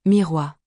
/mee-rwah/
miroix-pronunciation-4wIbzc6mmVaPs9nW.mp3